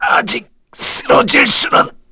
Dimael-Vox_Dead_kr.wav